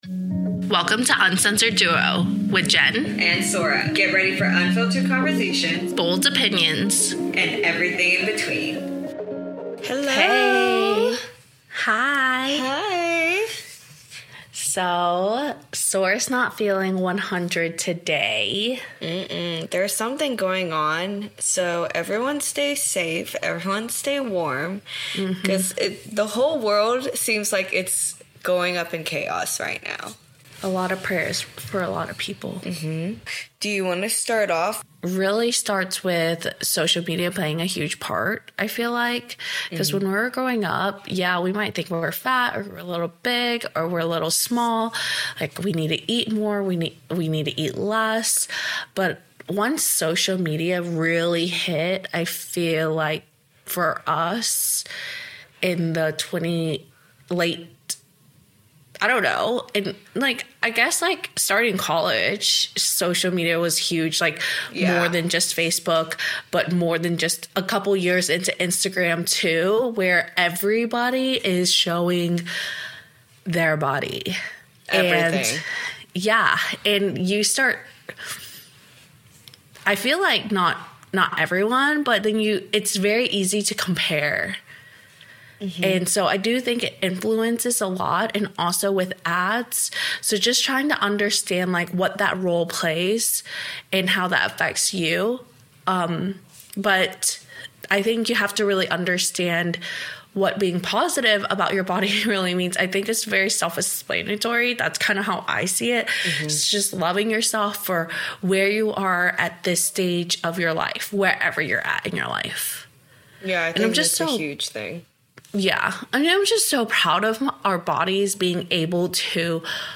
We discuss how social media has shaped the idea of the 'ideal' body, the impact it has on self-esteem, and how we can reclaim and embrace diverse body types. Tune in for an honest conversation on the challenges and growth that come with loving yourself in a world that often promotes unrealistic expectations.